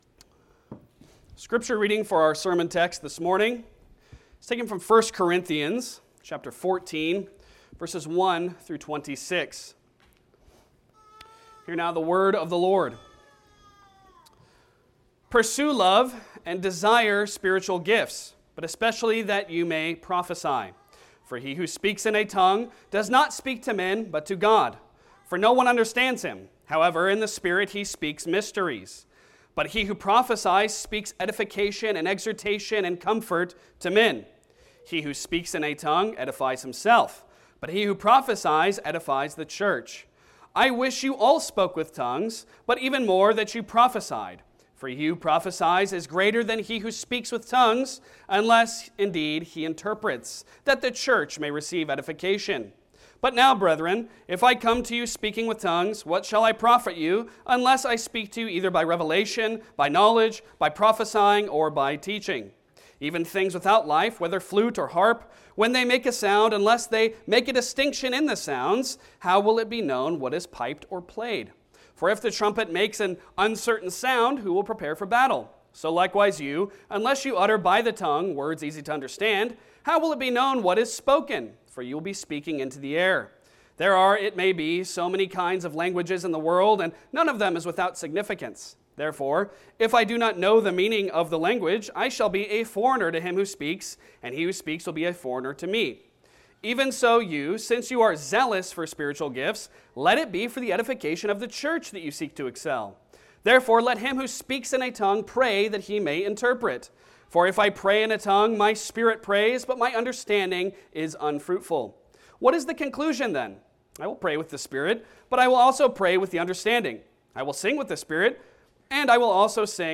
Passage: 1 Corinthians 14:1-26 Service Type: Sunday Sermon